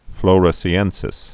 (flōrə-sē-ĕnsĭs)